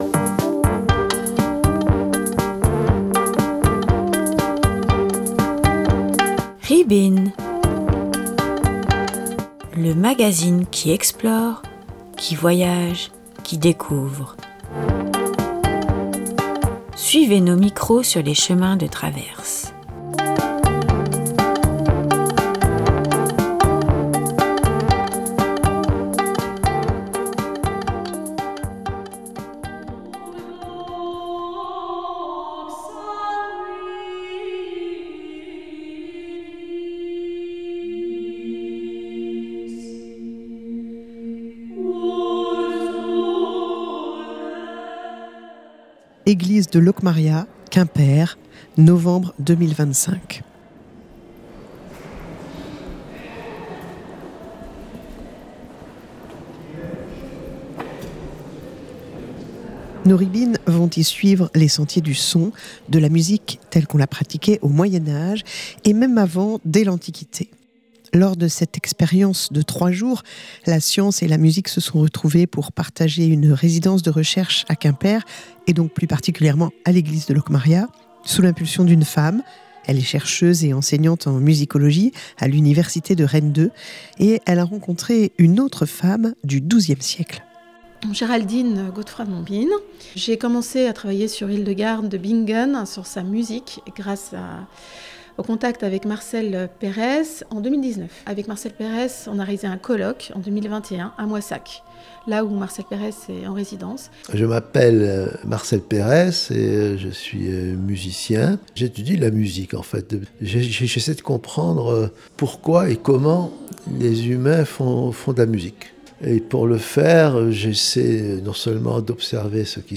Une résidence de recherche autour de la musique composée par Hildegarde de Bingen, abbesse allemande du XIIe siècle, à l'église de Locmaria de Quimper. Au programme, des chants en lien avec les lieux et une réflexion sur l'essence originelle de la musique.